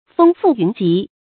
蜂附云集 注音： ㄈㄥ ㄈㄨˋ ㄧㄨㄣˊ ㄐㄧˊ 讀音讀法： 意思解釋： 比喻人眾從各處聚集。